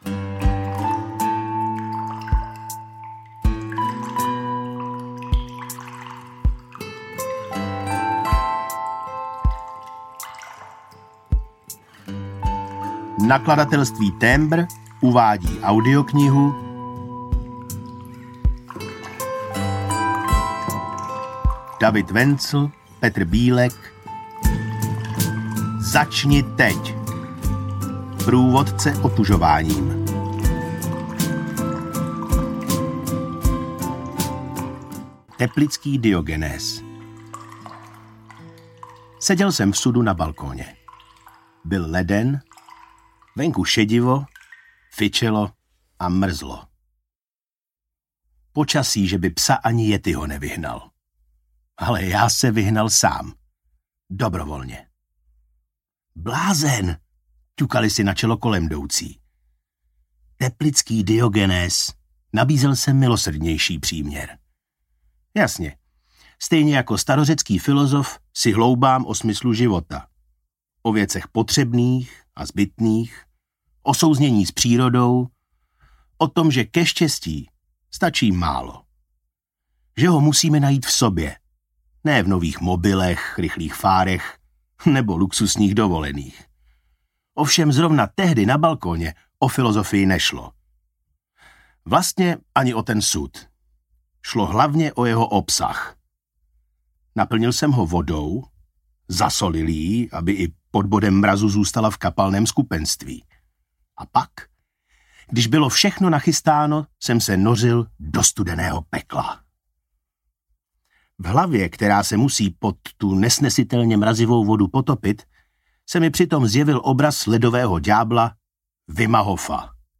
Začni teď! Průvodce otužováním audiokniha
Ukázka z knihy
• InterpretKryštof Rímský